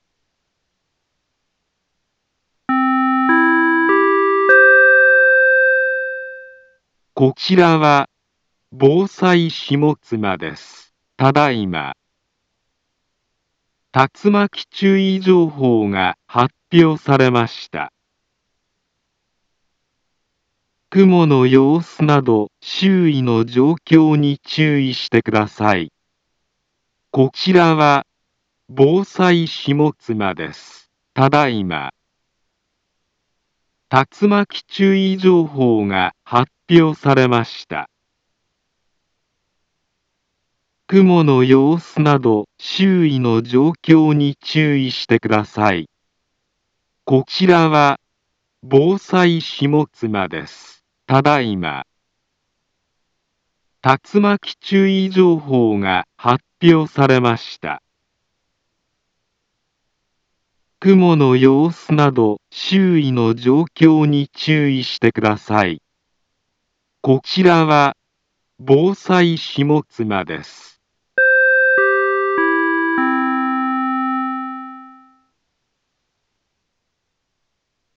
Back Home Ｊアラート情報 音声放送 再生 災害情報 カテゴリ：J-ALERT 登録日時：2025-07-23 15:48:24 インフォメーション：茨城県北部、南部は、竜巻などの激しい突風が発生しやすい気象状況になっています。